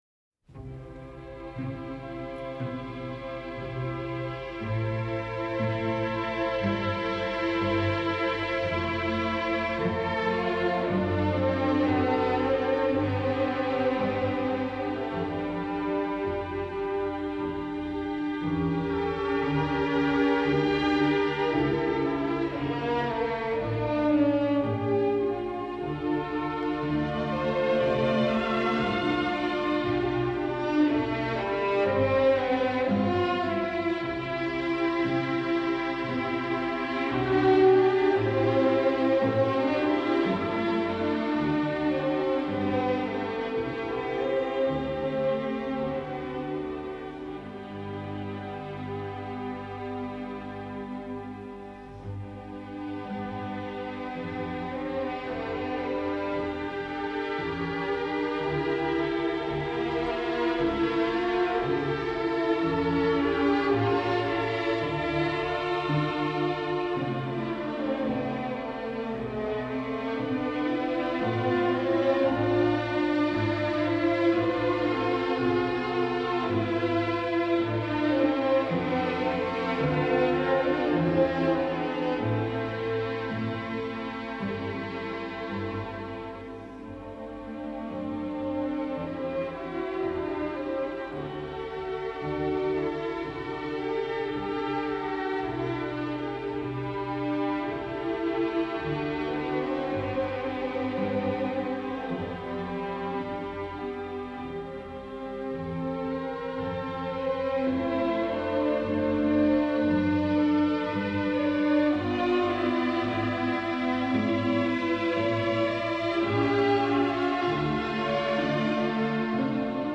12_Paul_Antioch_Iconium_1024Air on the G String is August Wilhelmj‘s arrangement of the second movement in Johann Sebastian Bach‘s Orchestral Suite No. 3 in D major, BWV 1068.